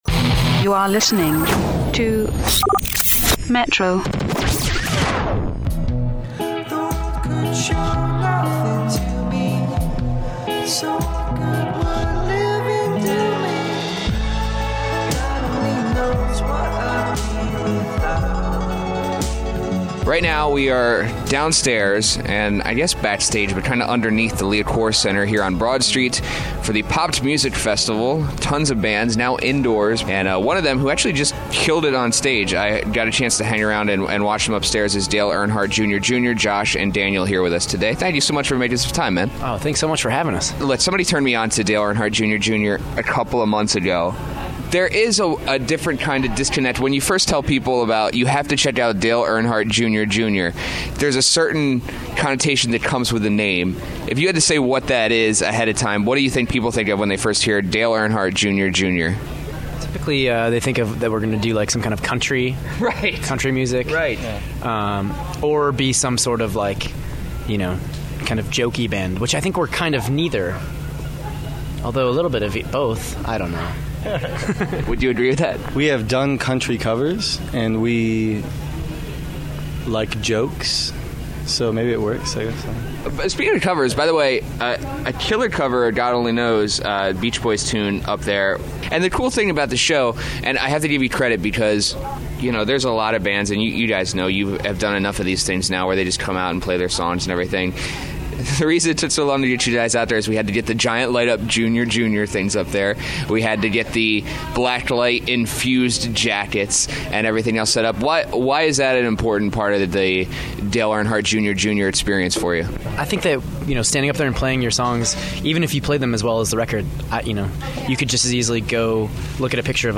Straight out of Detroit and onto a weird couch in the basement of The Liacouras Center…It’s Dale Earnhardt Jr Jr. They played a great set at The Popped Music Festival and were nice enough to hang out a bit before they ventured back to the Motor City.